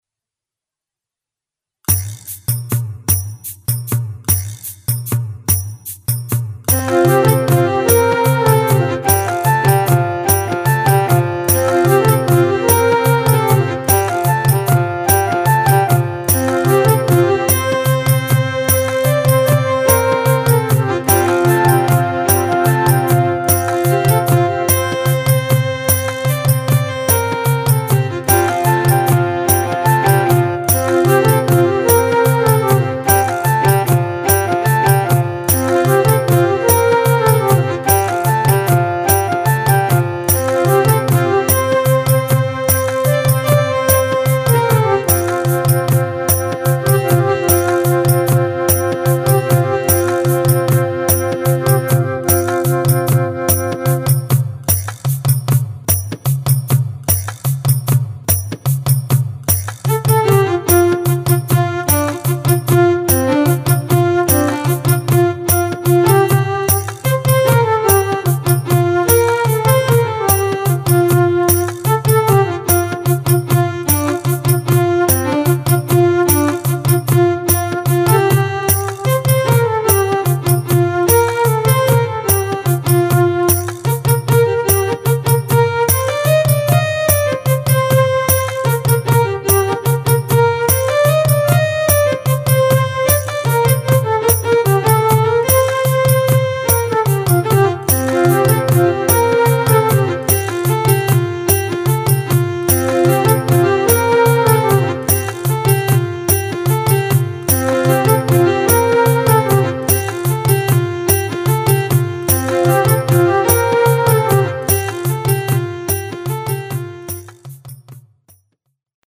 നാലാം എപ്പിസോഡായ "നാടോടിപ്പാട്ട്" ഇന്ന് തുടങ്ങുകയാണ്.
പല്ലവി തുടങ്ങുന്നത് : 0.06 മുതൽ. അവസാനിക്കുന്നത് : 0.30 ന്.
ആവർത്തന പല്ലവി : 0.31 മുതൽ 0.53 വരെ
അനുപല്ലവി തുടങ്ങുന്നത് 0.59 മുതൽ. അവസാനിക്കുന്നത് 1.36 ന്
തുണ്ടുപല്ലവി : 1.37 മുതൽ തീരും വരെ.